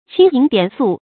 青蠅點素 注音： ㄑㄧㄥ ㄧㄥˊ ㄉㄧㄢˇ ㄙㄨˋ 讀音讀法： 意思解釋： 青蠅：蒼蠅，比喻進讒言的人；素：白色的生絹。